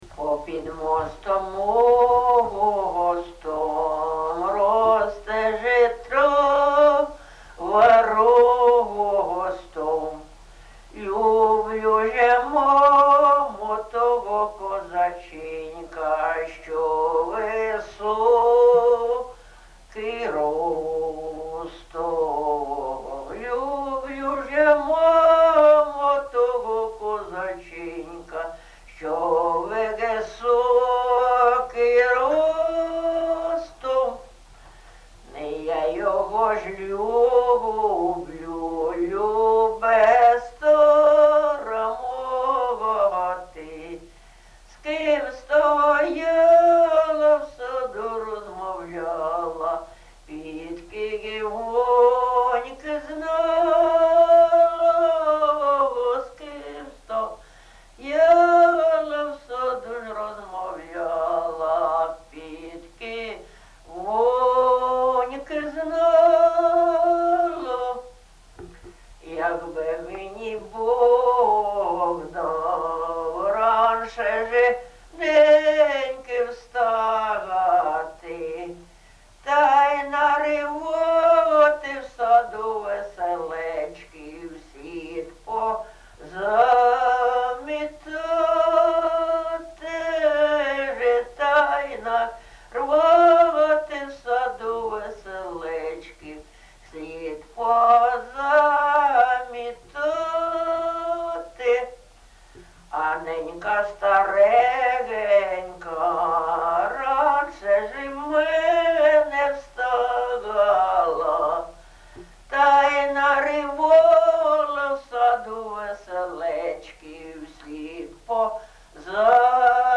ЖанрПісні з особистого та родинного життя
Місце записус. Софіївка, Краматорський район, Донецька обл., Україна, Слобожанщина